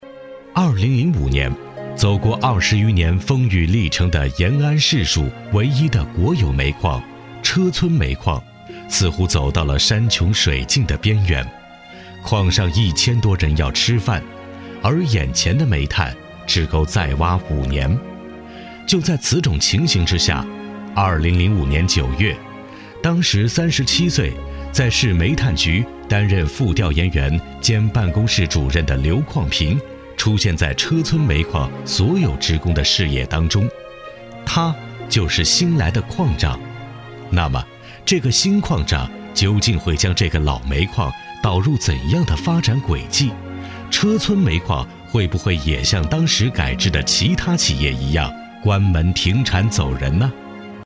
党政男158号（车村煤矿）
品质浑厚男音，自然风光，低沉磁性，厚重古韵。